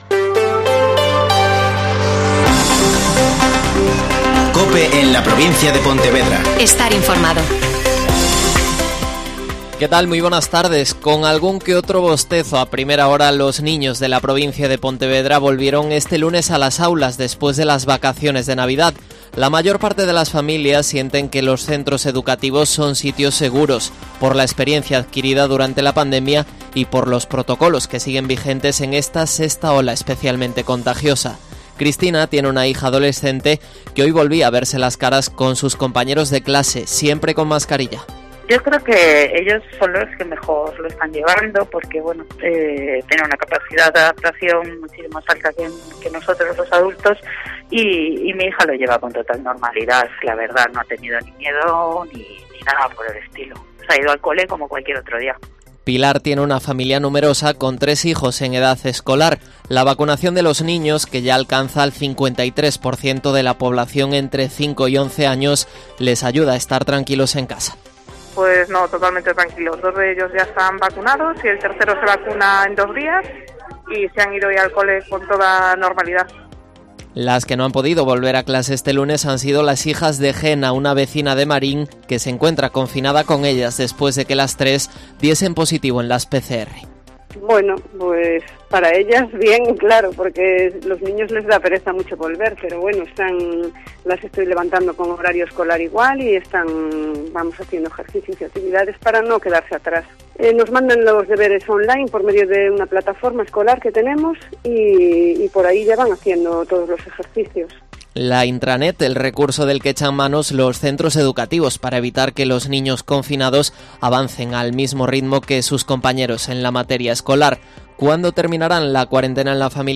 Mediodía COPE en la Provincia de Pontevedra (Informativo 14:20h)